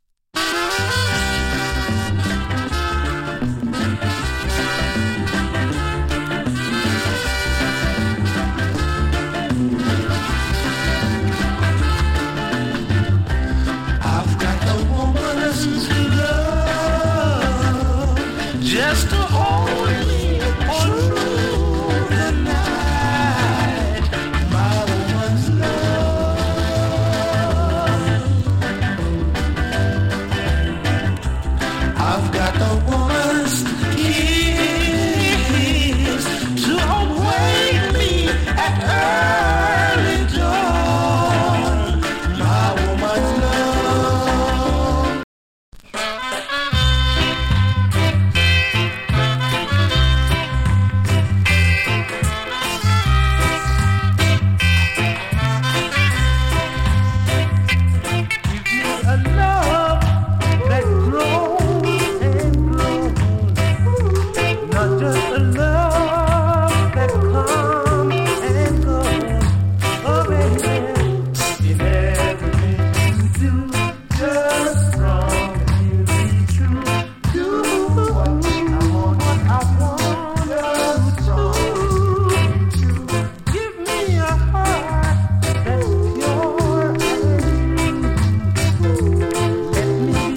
NICE ROCK STEADY × 2.